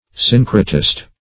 Syncretist \Syn"cre*tist\, n. [Cf. F. syncr['e]tiste.]